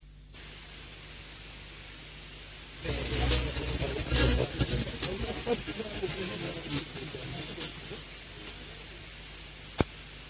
通常流星尾游離的時間很短， 聽到的訊號也在零點幾秒間， 聲音就像短促的撞擊聲或唧唧聲。
流星的聲音
10.4秒，長時間的流星聲。